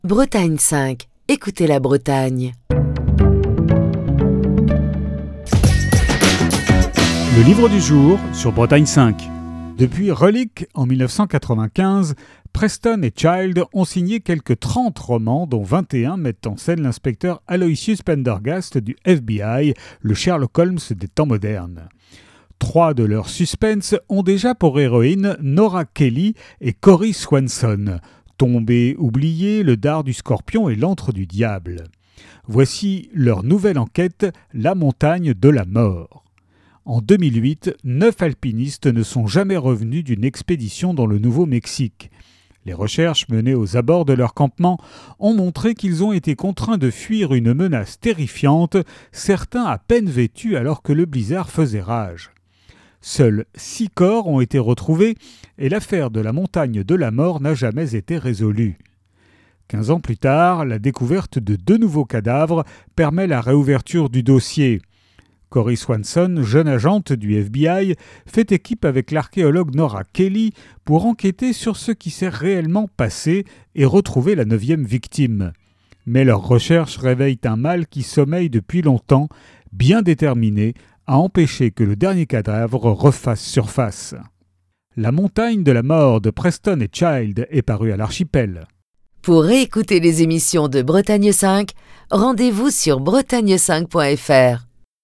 Chronique du 31 janvier 2025.